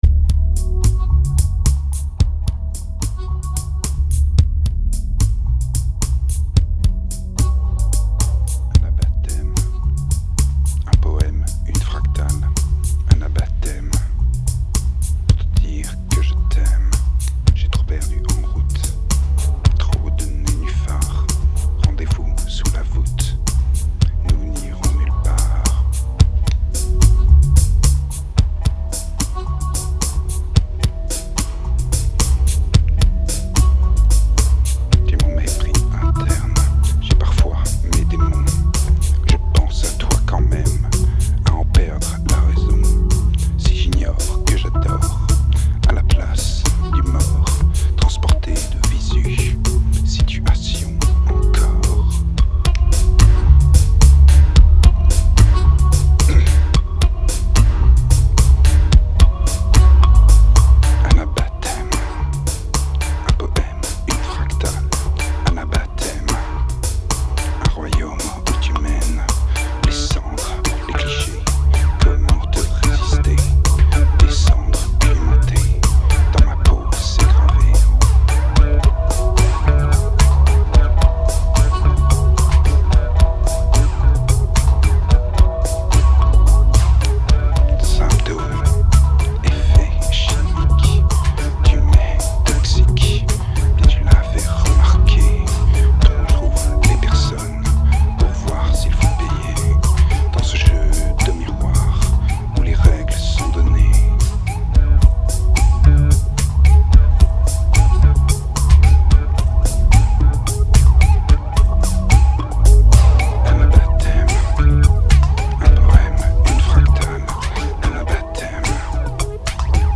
2210📈 - -11%🤔 - 110BPM🔊 - 2008-10-19📅 - -312🌟